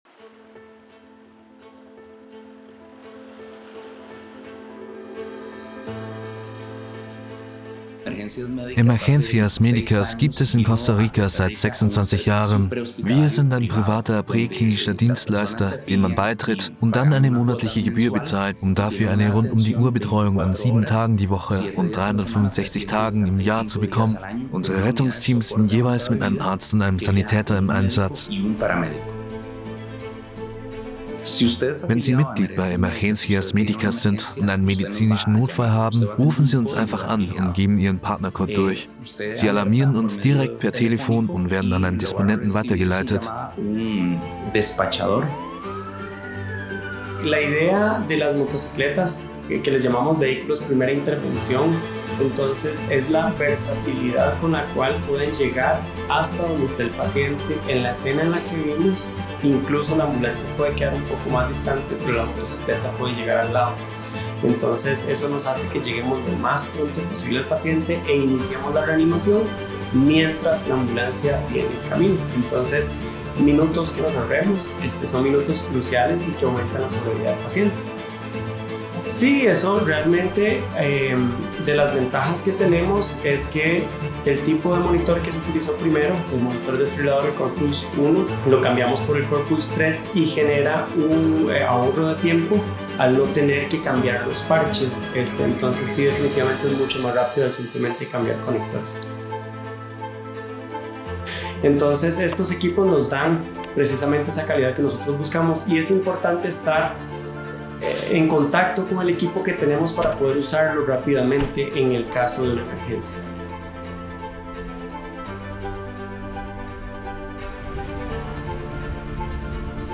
Documentary Emergencias Medicas
High german, bavarian